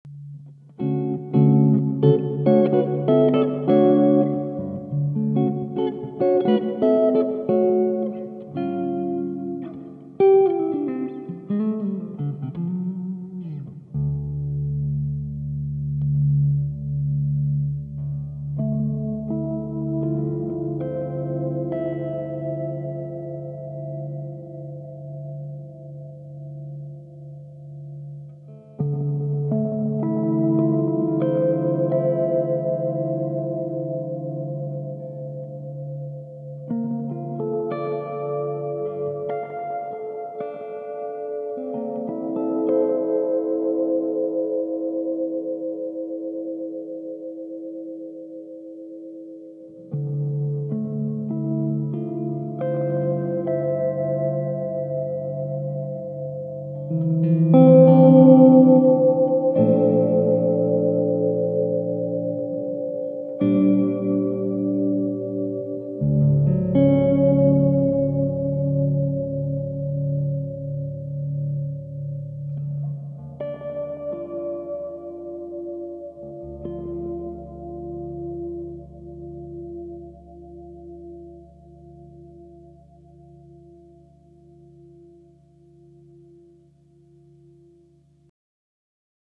Accutronics BTDR2-alapú reverb
0-0.7 effect off
0.7-0.15 level:30%, decay 0%
0.35-0.40 filter on
0.53-1:00 level:70%, decay 100%
REVEWRB.mp3